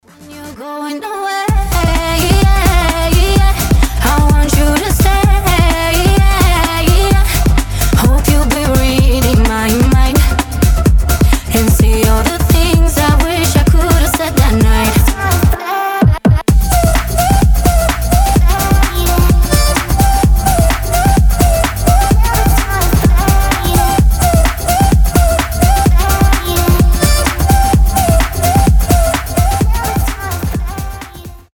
танцевальные
dance pop , красивый женский голос